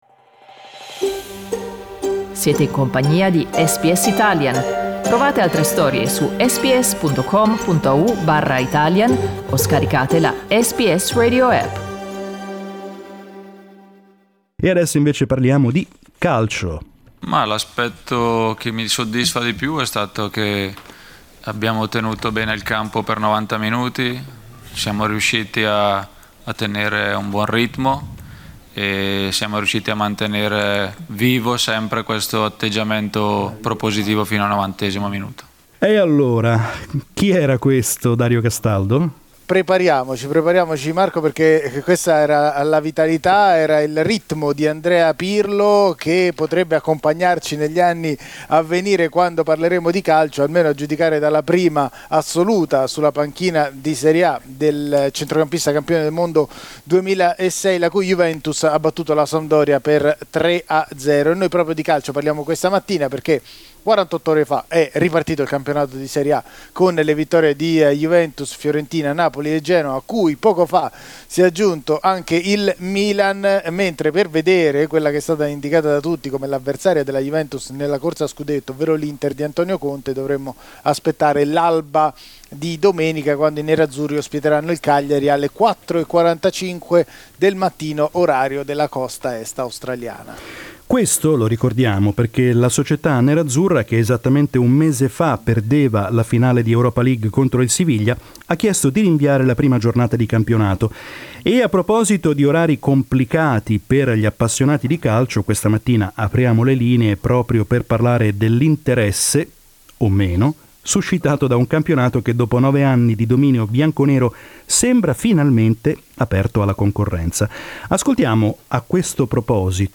Per questo, al termine del primo weekend calcistico, abbiamo chiesto agli ascoltatori di SBS Italian di raccontare come guardano le partite, nonostante il fuso orario a dir poco sfavorevole.